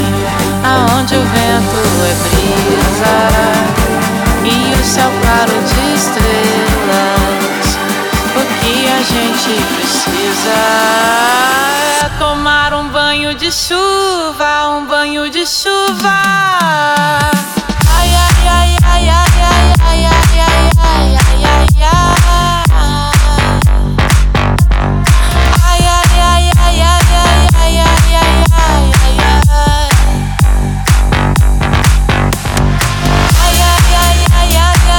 MPB Brazilian